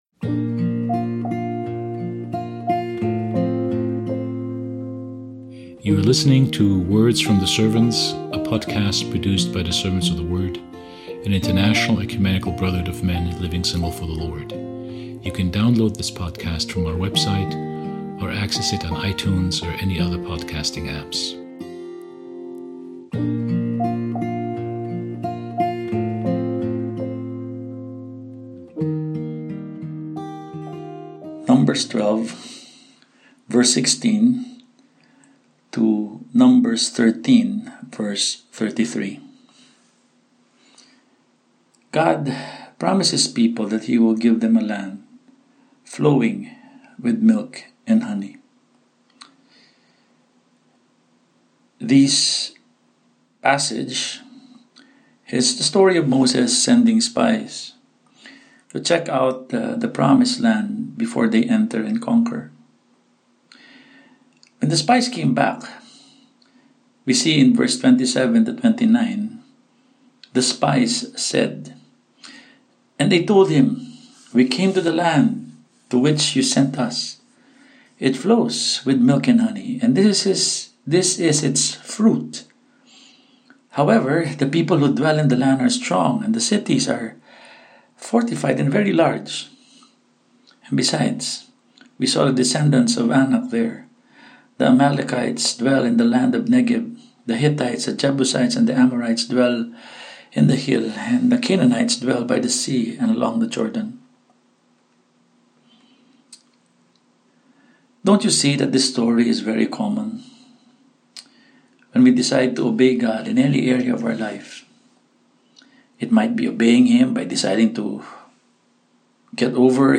Throughout the 40 Days of Lent, our Words from the Servants podcast will feature brothers from around the world as they give daily commentary on the Pentateuch and the book of Hebrews .